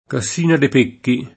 kaSS&na de p%kki]; Cassina Rizzardi [kaSS&na riZZ#rdi]; Cassina Valsassina [kaSS&na valS#SSina] — cfr. cascina